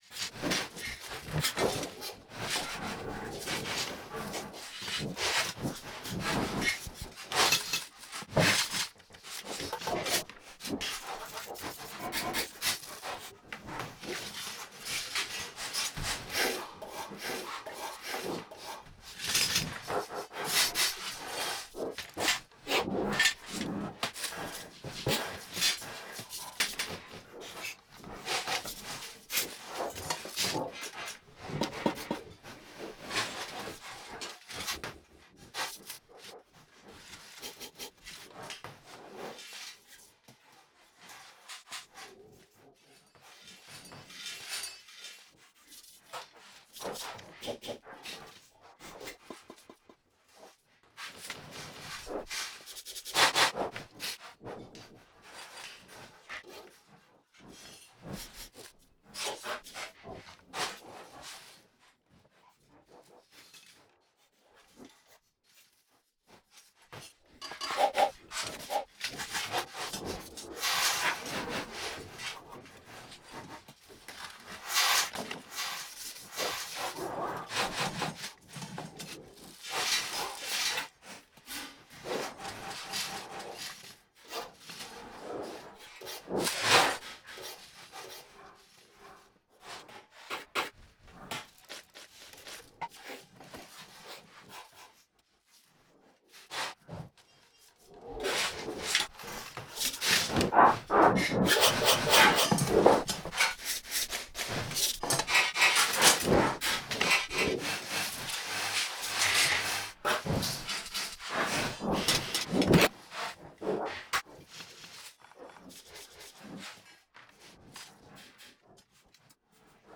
- [ flac ] [ mp3 ] Algorithm running on audio samples obtained by recording the artists at work.